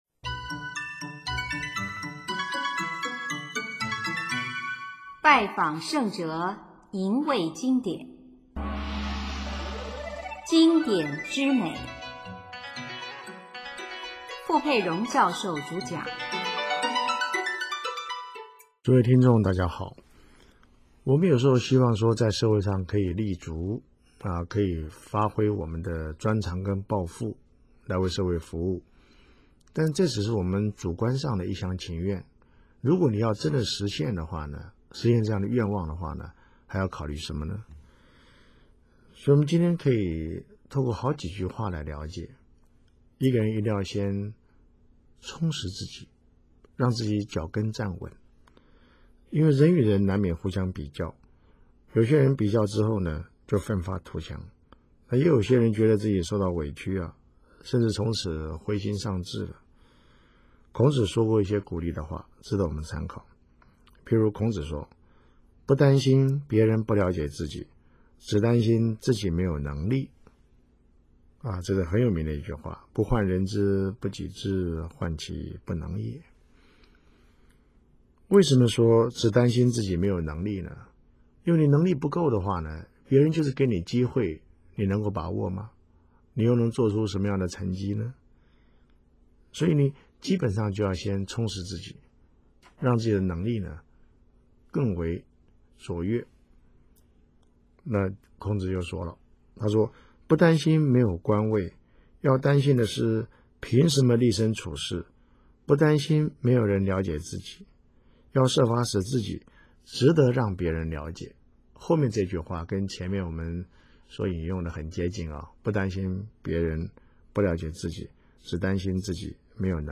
主讲：傅佩荣教授